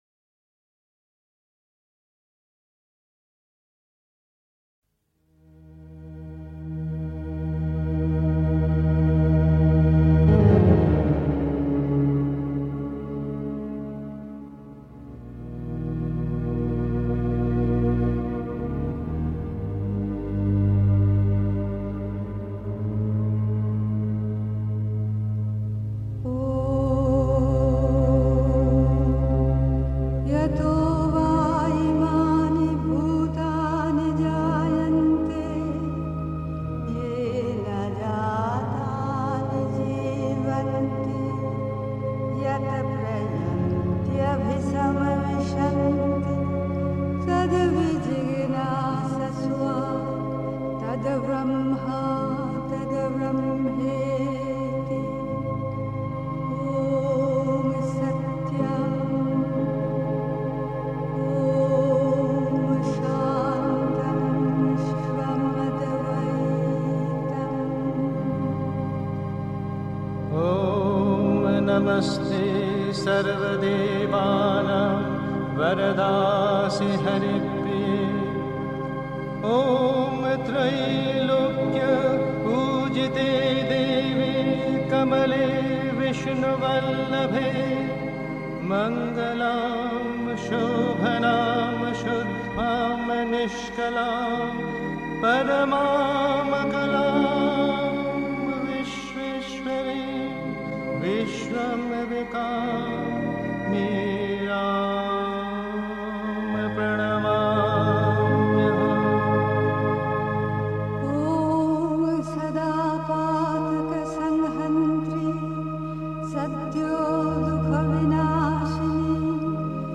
Pondicherry. 2. Das große Geheimnis, alle unerfreulichen Dinge los zu werden (Die Mutter, White Roses, 06 March 1956) 3. Zwölf Minuten Stille.